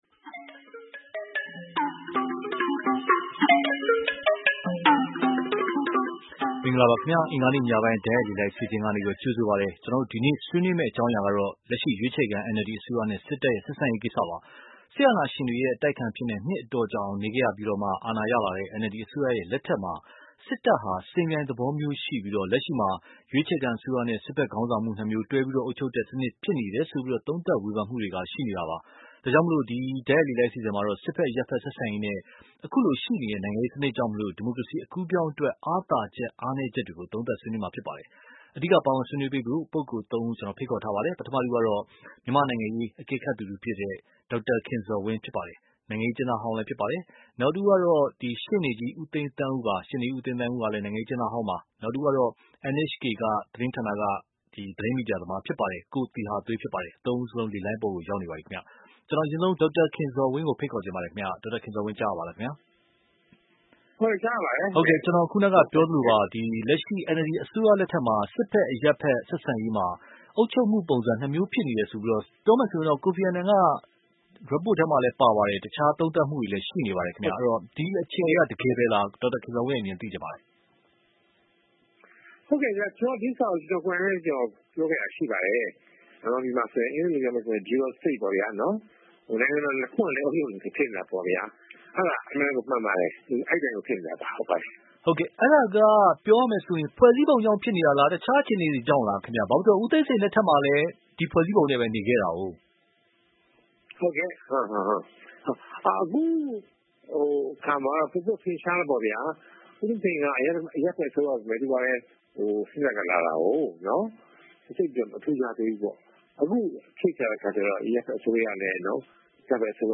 ရွေးချယ်ခံအစိုးရနဲ့ စစ်တပ်ဆက်ဆံရေး (တိုက်ရိုက်လေလှိုင်း)